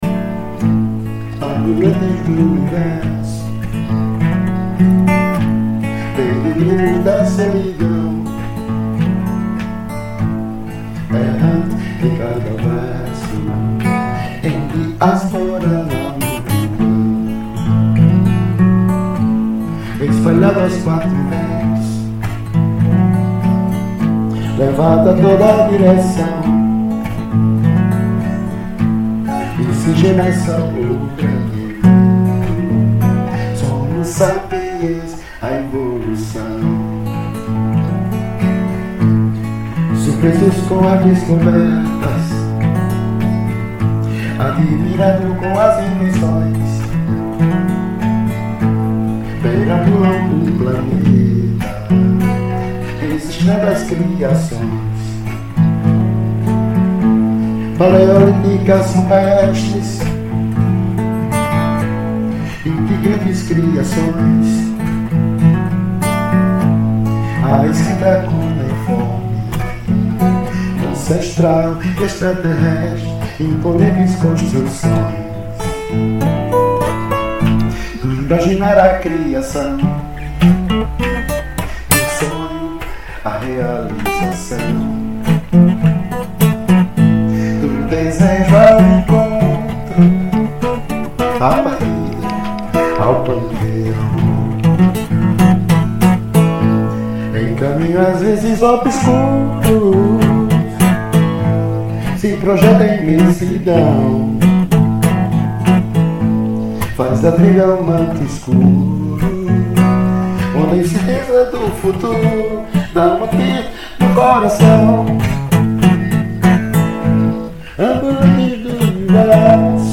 EstiloJazz